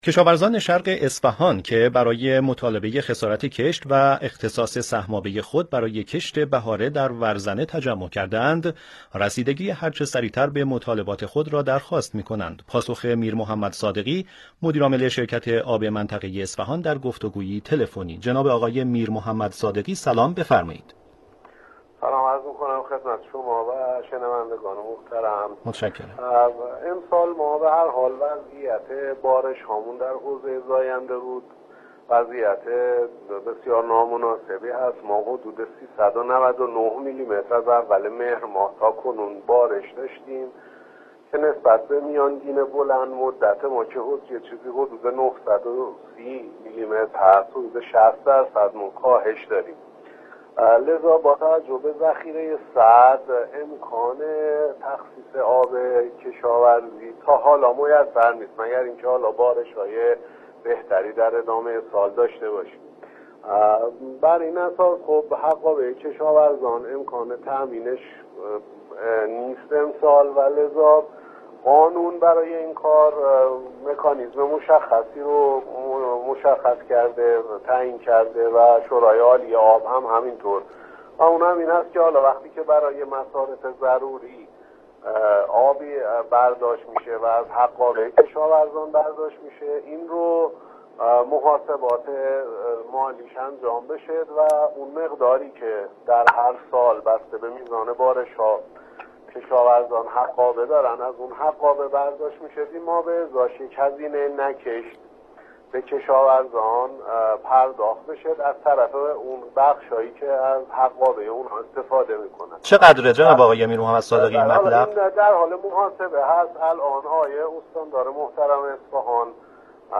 پاسخ مسوولان به درخواست کشاورزان + مصاحبه رادیویی